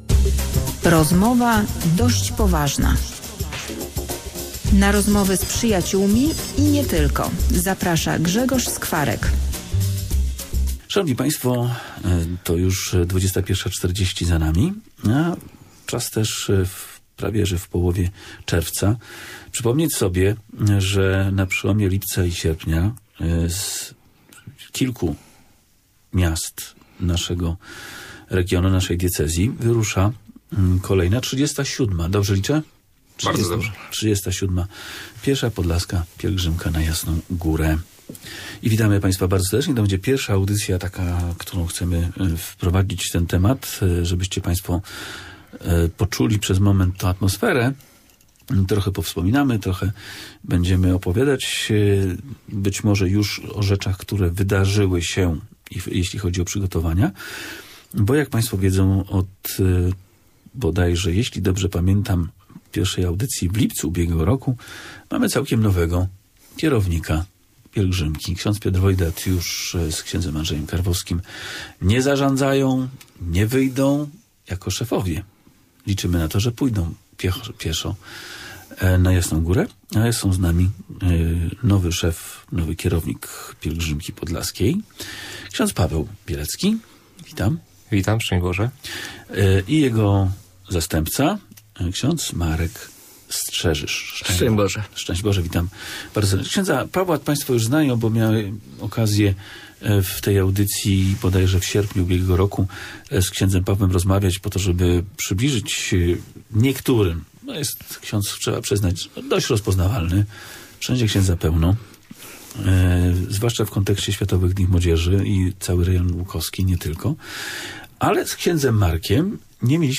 Pierwsza audycja pielgrzymkowa